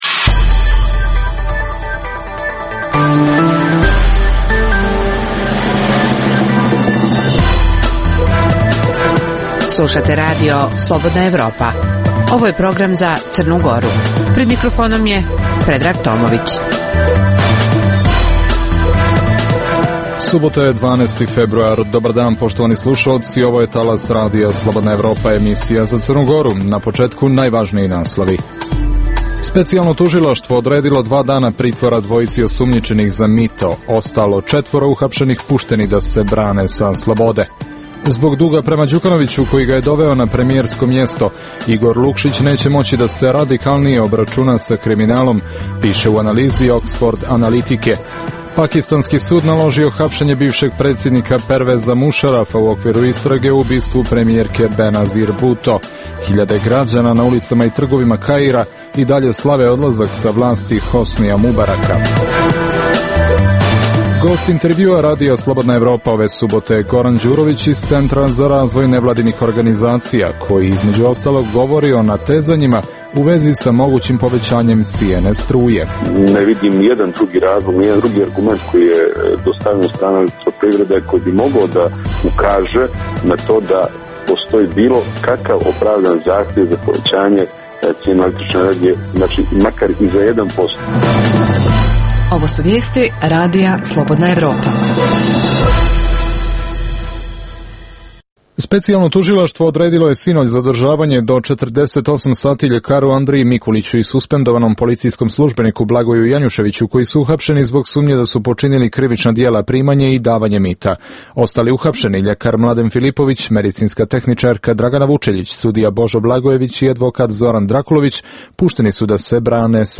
Emisija namijenjena slušaocima u Crnoj Gori. Sadrži lokalne, regionalne i vijesti iz svijeta, tematske priloge o aktuelnim dešavanjima iz oblasti politike, ekonomije i slično, te priče iz svakodnevnog života ljudi, kao i priloge iz svijeta.